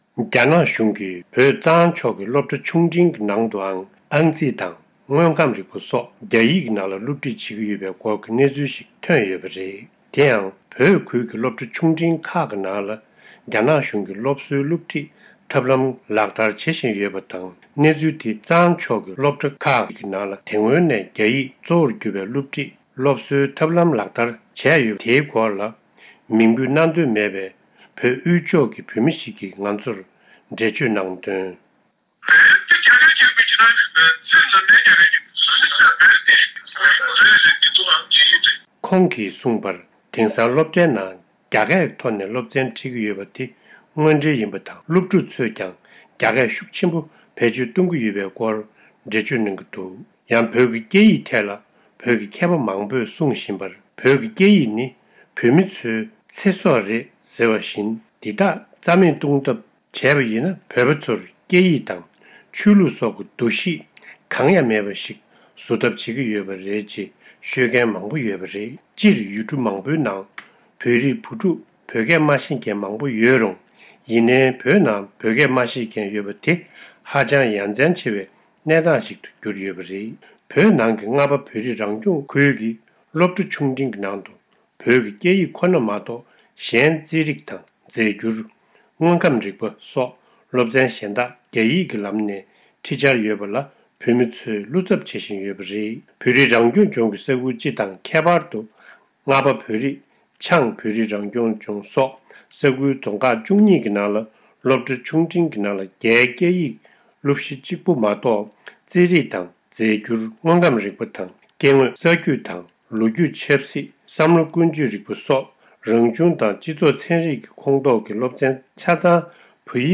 བོད་ནས་བཏང་འབྱོར།
སྒྲ་ལྡན་གསར་འགྱུར། སྒྲ་ཕབ་ལེན།